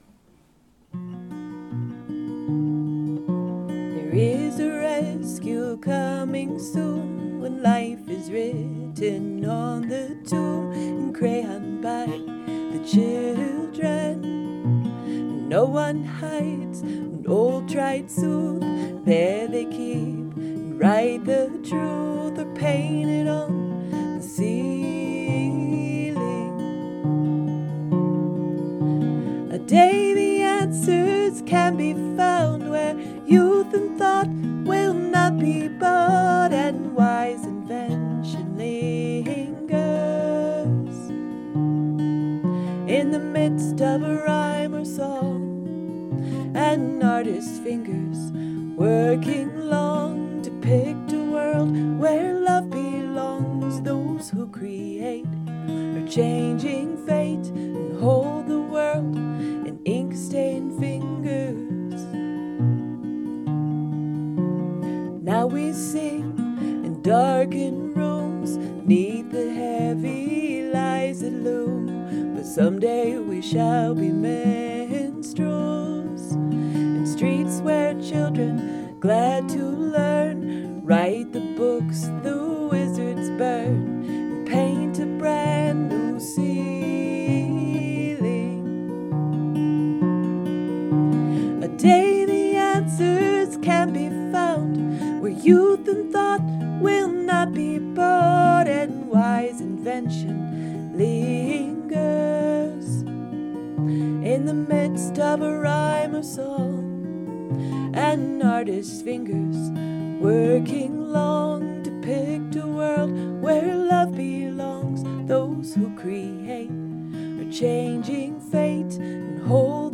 capo 3